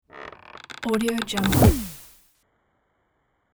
دانلود افکت صوتی Creaking Super Sweep
یک گزینه عالی برای هر پروژه ای است که نیاز به انتقال و حرکت و جنبه های دیگر مانند صدای فریاد، swoosh و sweep دارد.